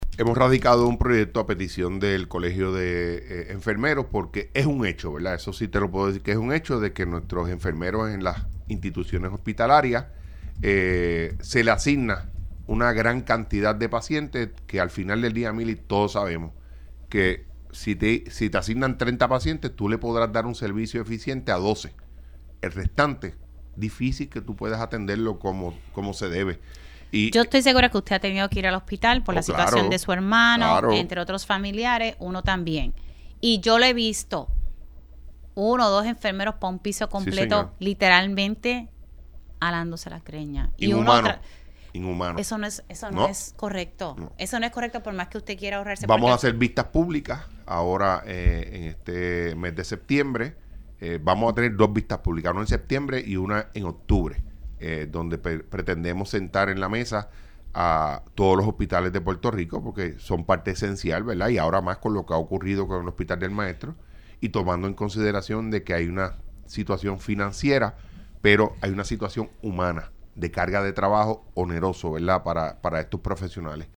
El senador del Partido Nuevo Progresista (PNP), Juan Oscar Morales Rodríguez, anunció en Pega’os en la Mañana que sometió el Proyecto del Senado 687 para limitar la cantidad de pacientes por enfermeros y reducir la migración de profesionales puertorriqueños en el campo de la medicina.